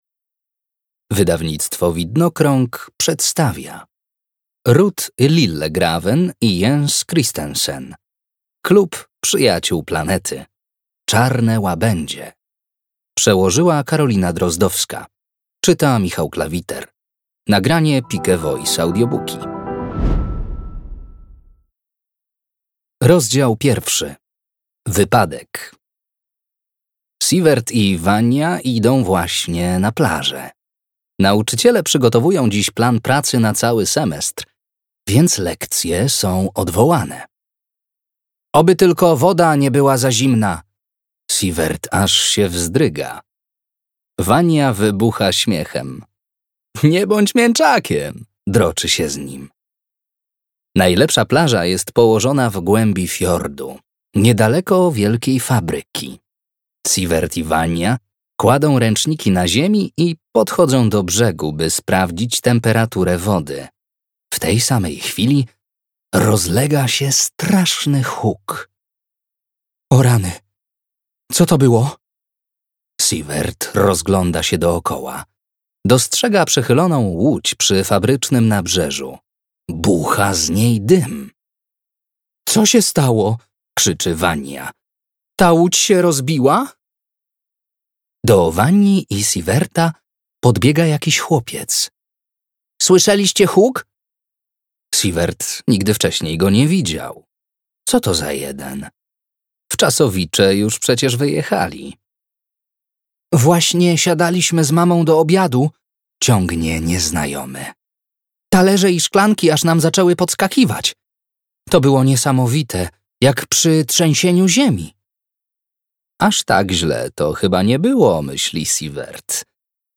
Klub Przyjaciół Planety. Czarne łabędzie - Ruth Lillegraven - audiobook